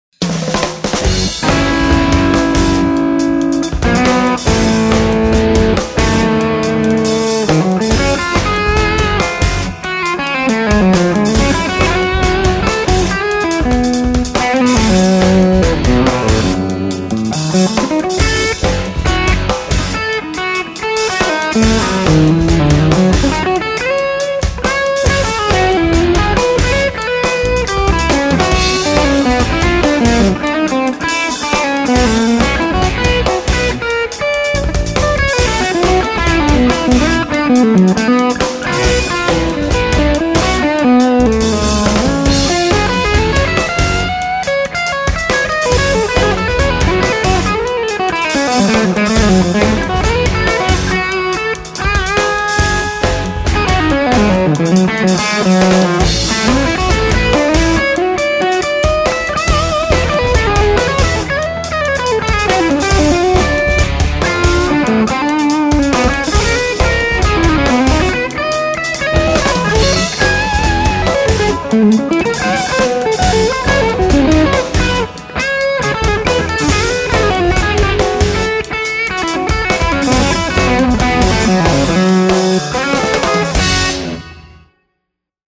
This is HRM. No snubbers and choke on.
Clip 1 - V1 RCA long plate, V2 RFT
Same exact settings and mic between clips.
I hear a tad more high end brashness after the attack on the first clip - some more high end "hair".
I thought the first one sounded articulate and bright ina good way.